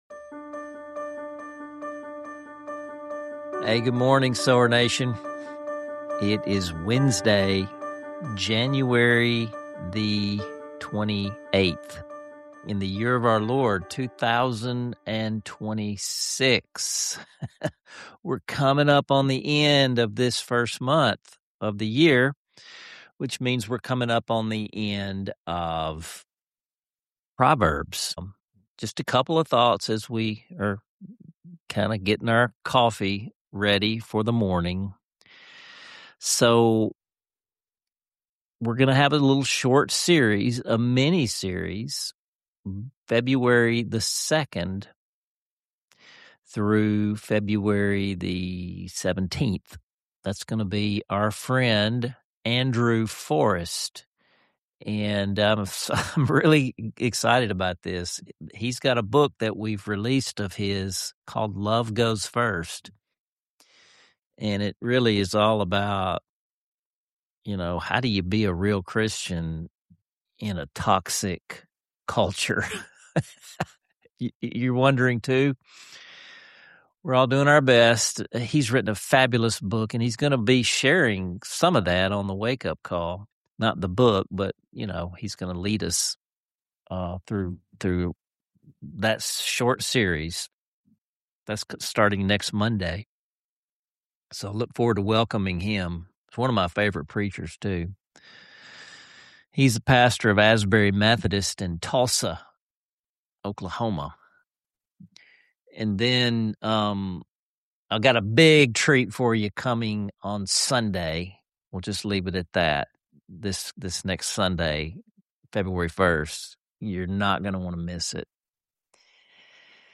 The episode features a powerful musical moment from Psalm 51 that invites listeners into a posture of openness and divine renewal.